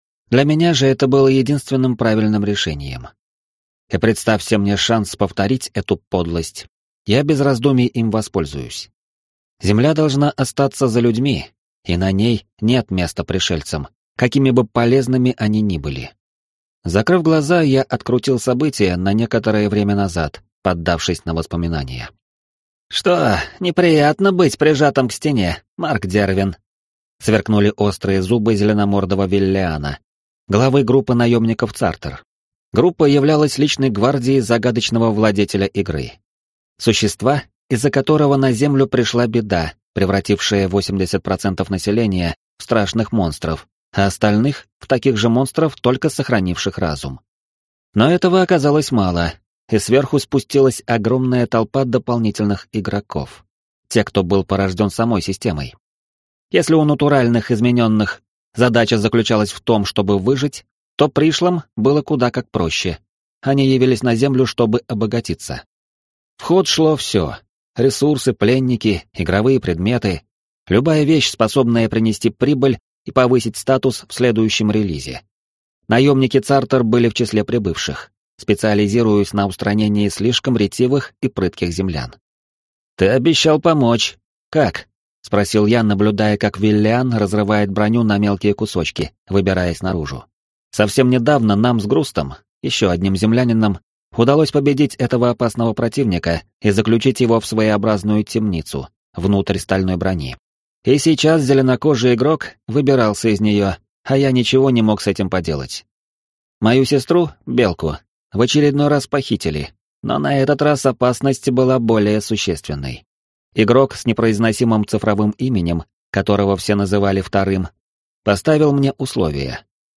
Аудиокнига Мир измененных. Книга 3. Воплощенный ноа | Библиотека аудиокниг